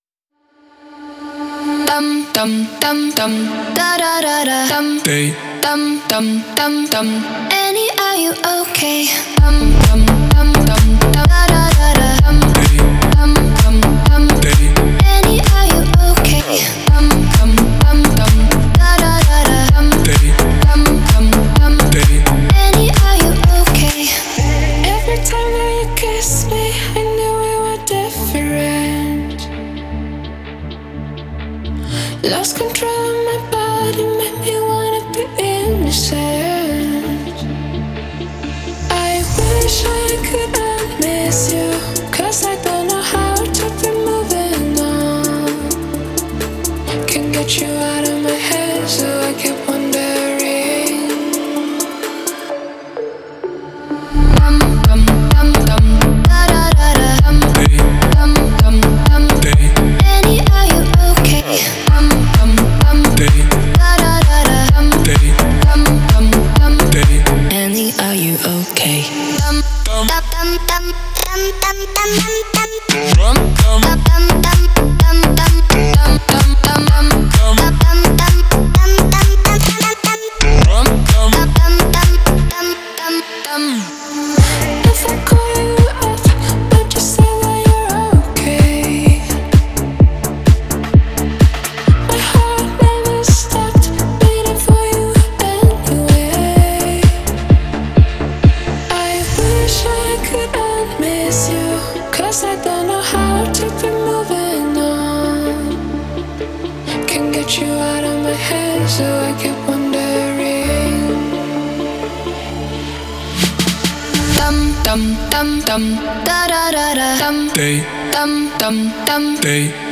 это динамичная электронная композиция